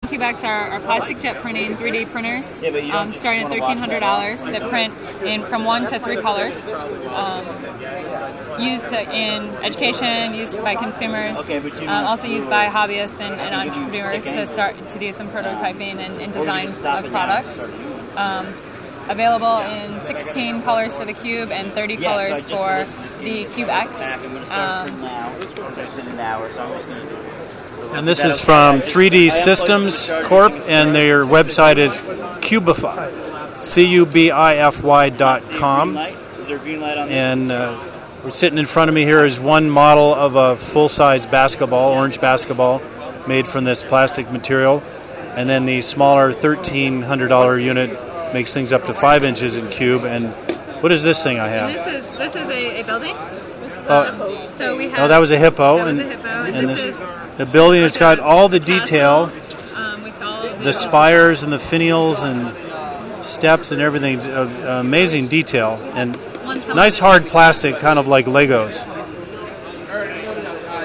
Tuesday Touring the exhibits at CES 2013
3D printer audio description